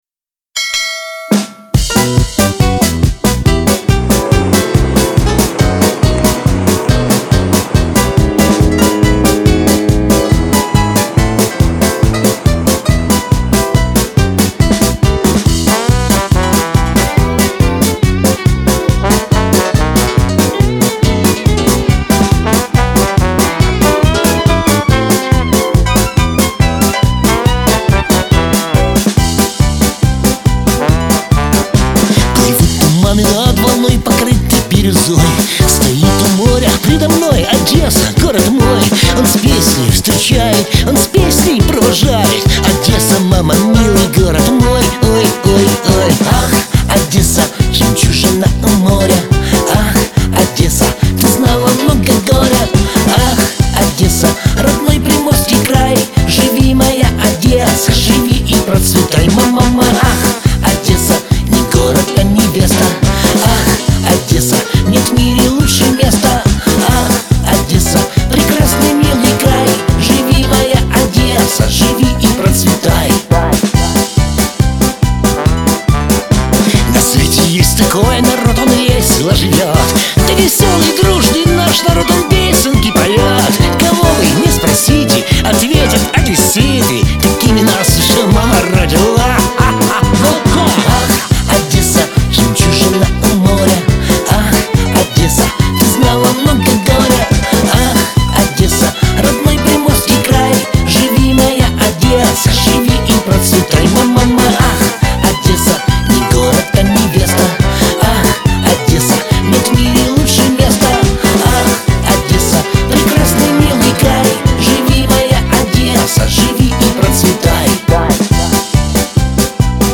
Всі мінусовки жанру Shanson
Плюсовий запис
Неплохо записано.